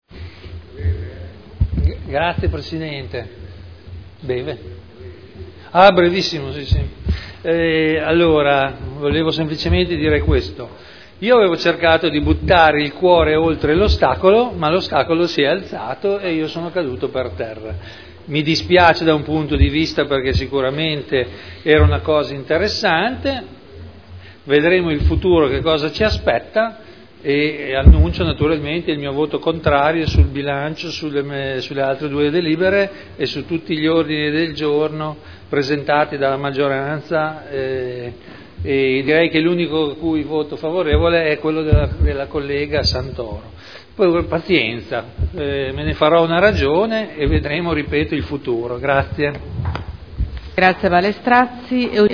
Vittorio Ballestrazzi — Sito Audio Consiglio Comunale
Seduta del 28/03/2011. Dichiarazioni di voto su delibere e Bilancio